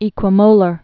(ēkwə-mōlər, ĕkwə-)